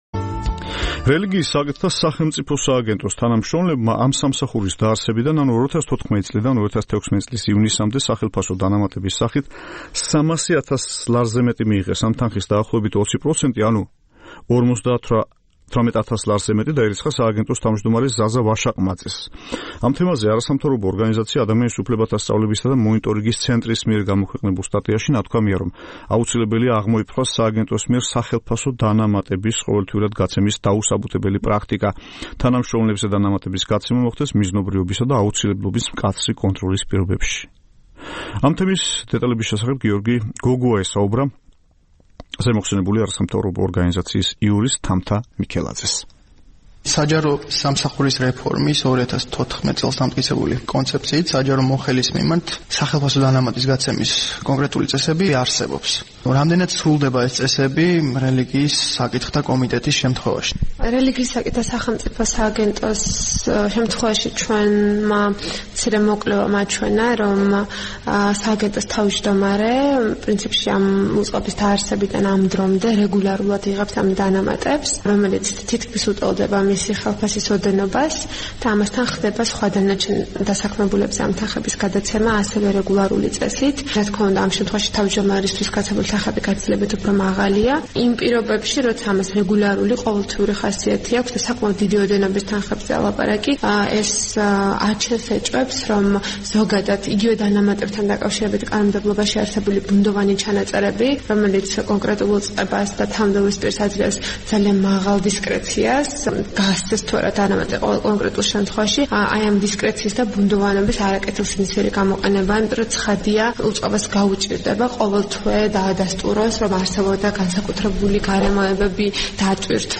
პრემიები რელიგიების მართვისთვის - ინტერვიუ